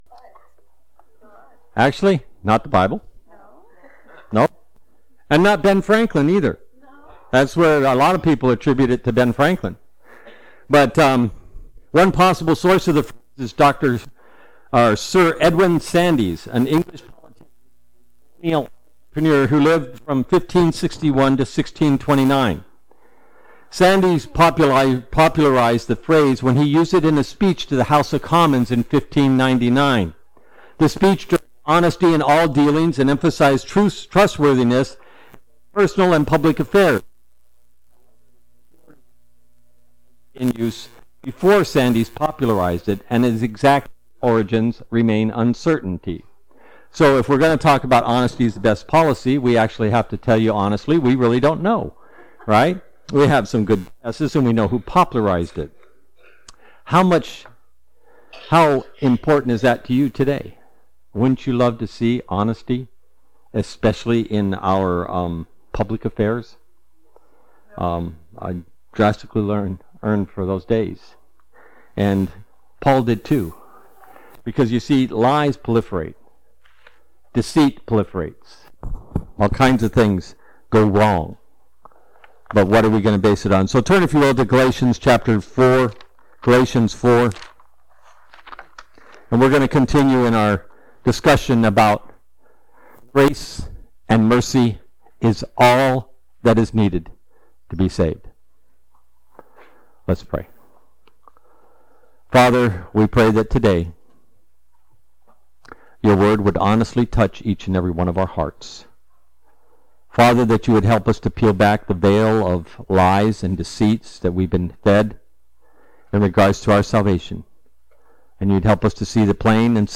All Sermons Honesty is the Best Policy 29 October 2023 Series